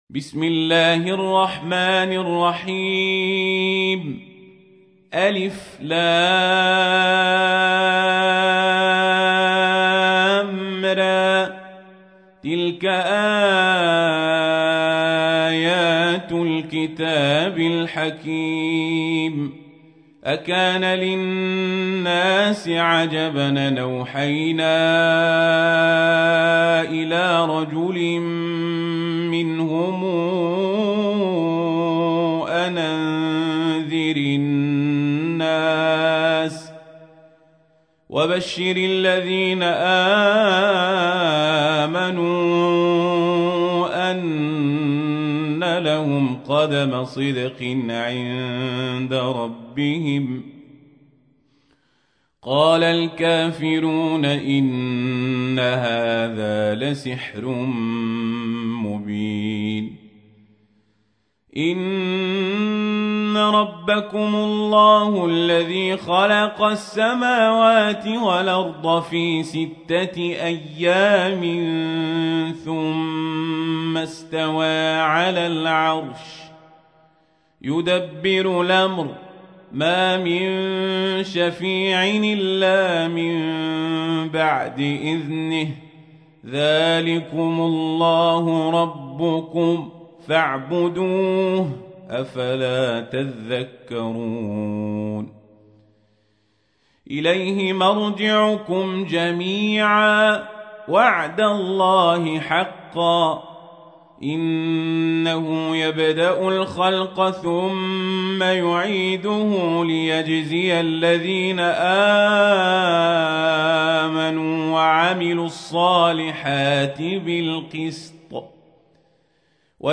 تحميل : 10. سورة يونس / القارئ القزابري / القرآن الكريم / موقع يا حسين